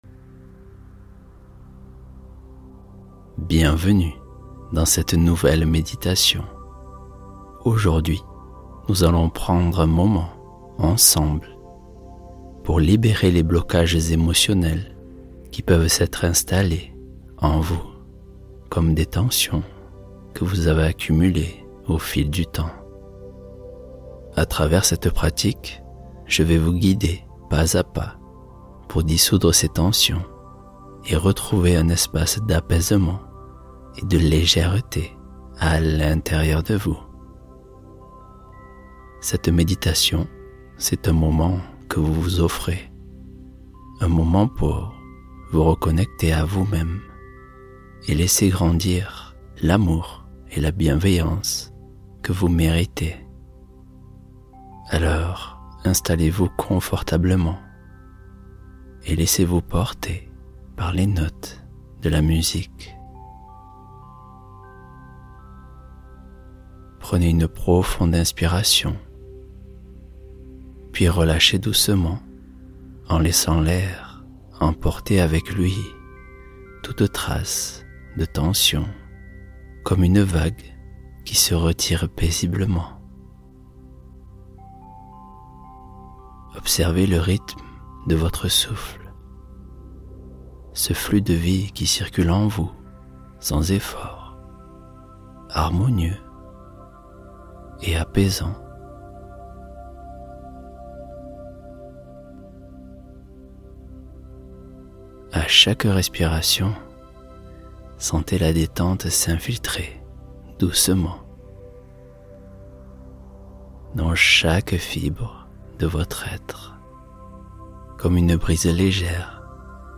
Relaxation Guidée